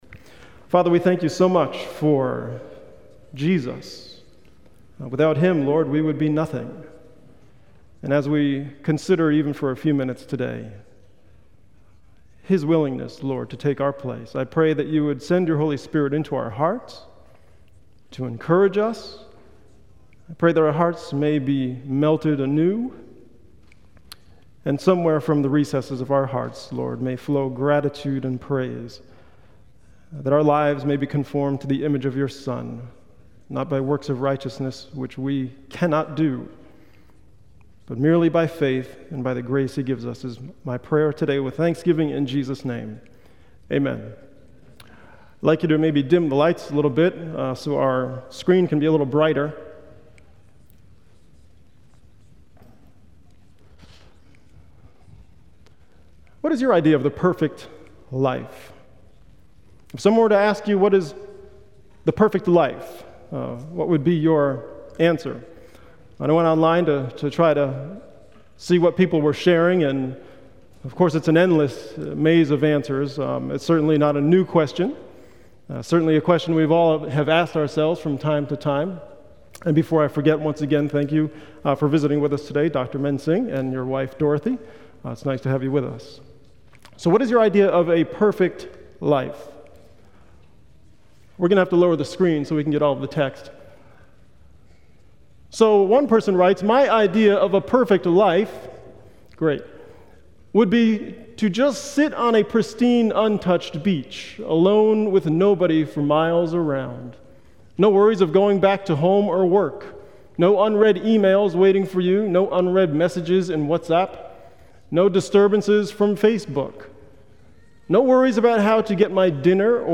on 2016-03-19 - Sabbath Sermons